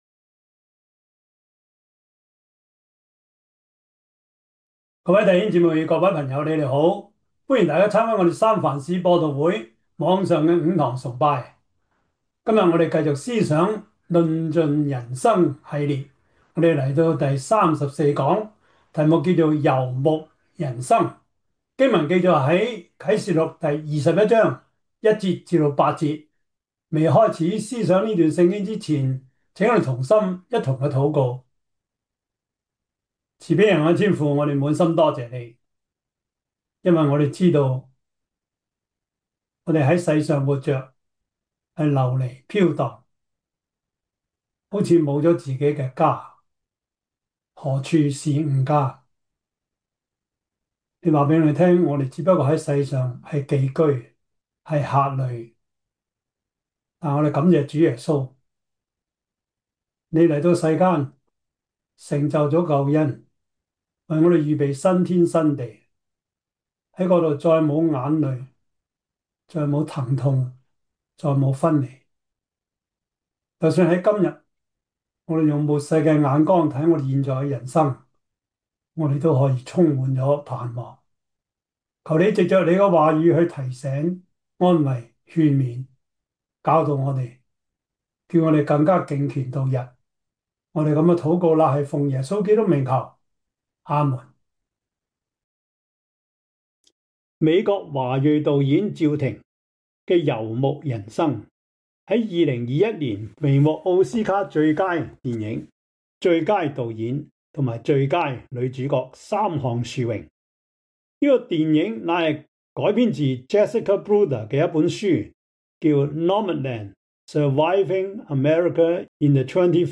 啟示錄 21:1-8 Service Type: 主日崇拜 啟示錄 21:1-9 Chinese Union Version
Topics: 主日證道 « 發拙恩賜